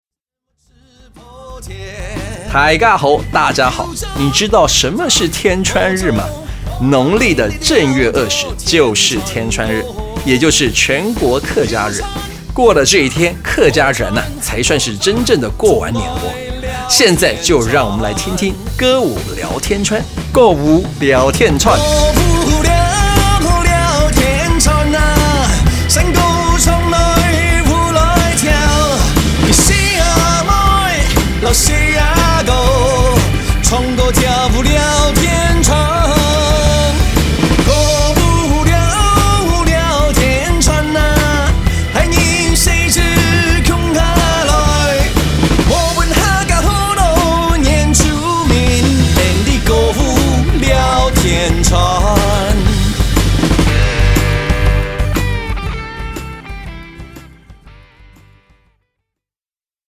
新北客語廣播(海陸)-天穿日版 | 新北市客家文化典藏資料庫